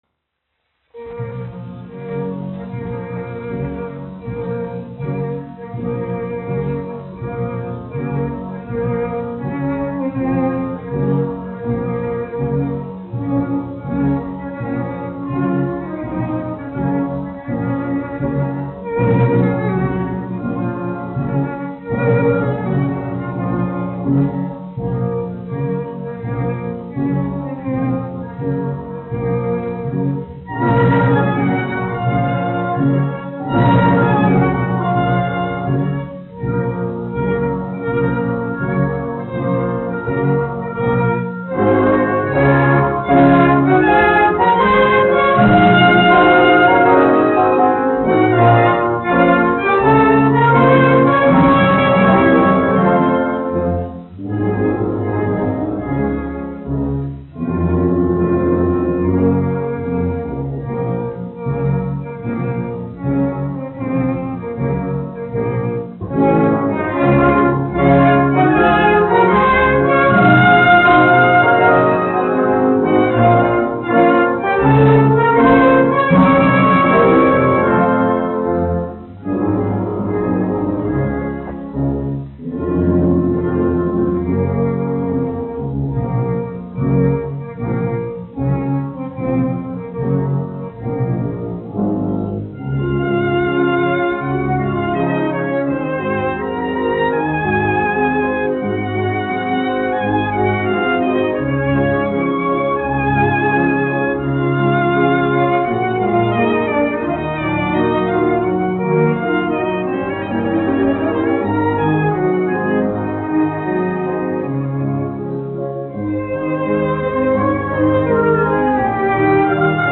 1 skpl. : analogs, 78 apgr/min, mono ; 25 cm
Sēru mūzika
Orķestra mūzika, aranžējumi
Skaņuplate
Latvijas vēsturiskie šellaka skaņuplašu ieraksti (Kolekcija)